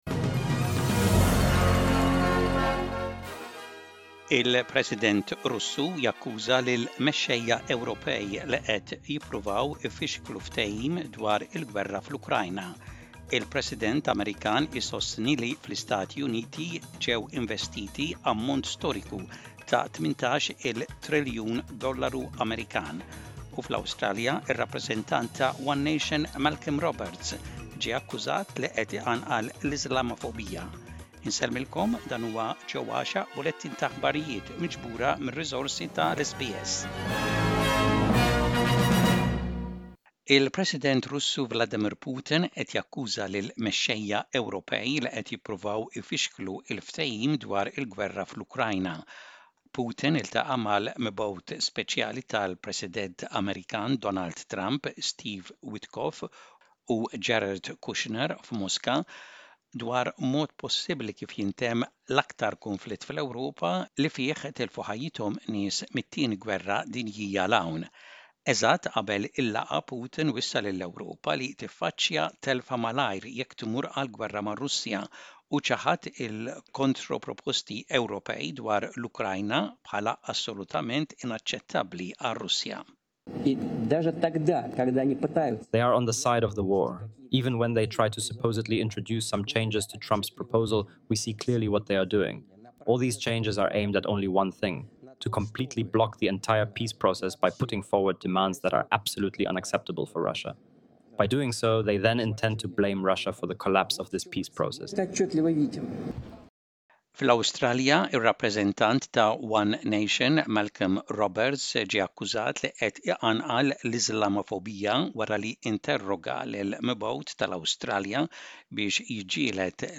SBS Maltese News - Image-SBS Maltese